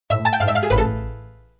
Sons système / System sounds